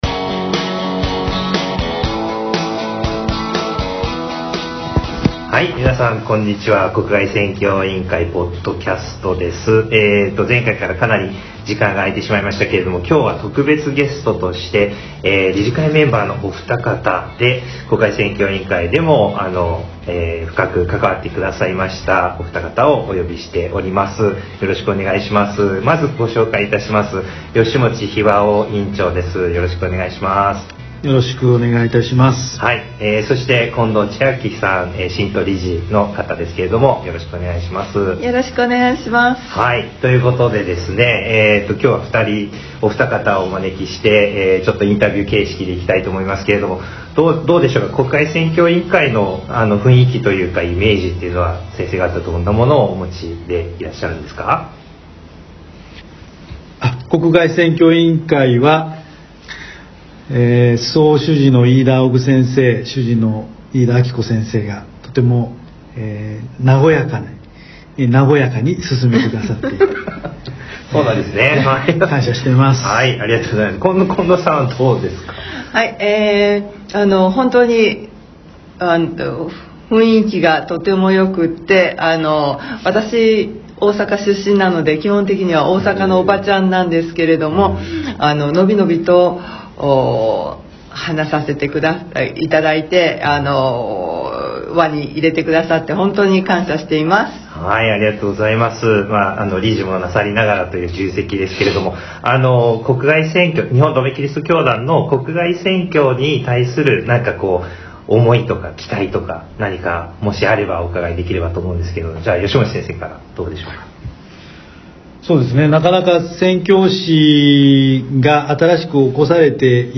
国外宣教委員会ポッドキャスト 特別対談編